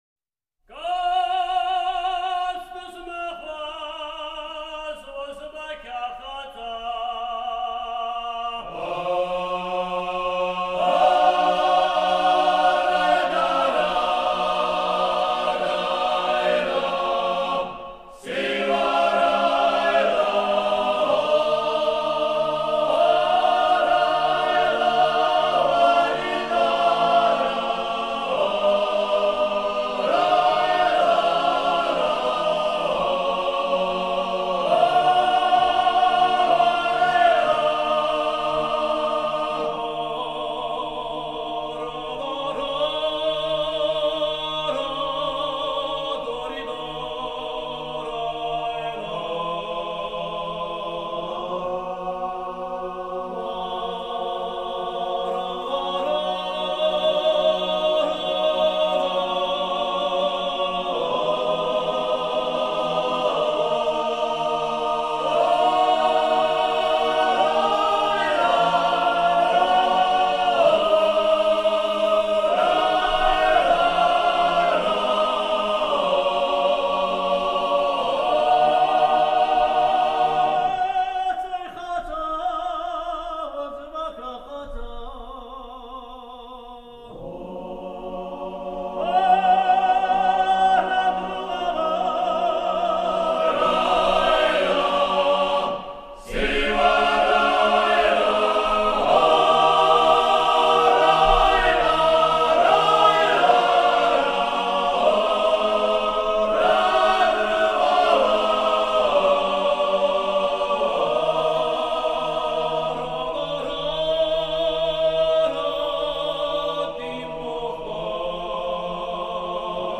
Apkhazian folk songs